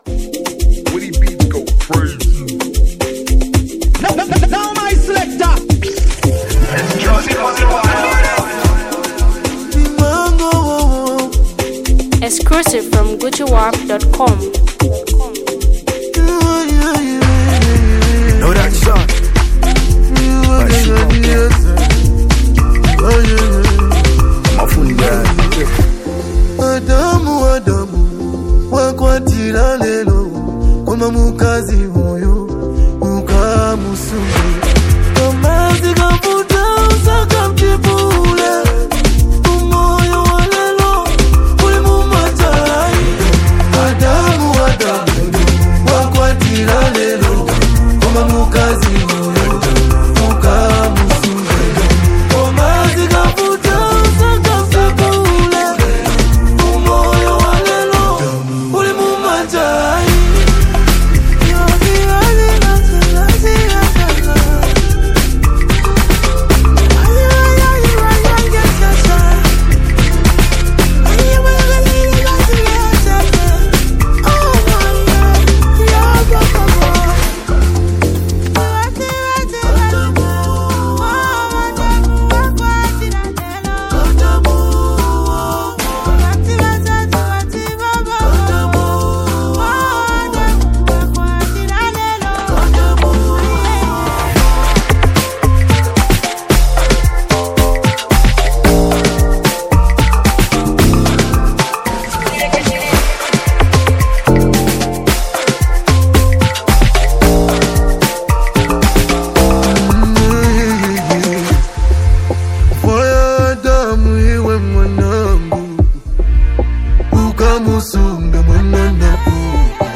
melodic flow
sharp rap verses